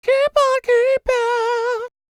DD FALSET116.wav